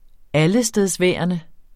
Udtale [ ˈaləsdεðsˌvεˀʌnə ]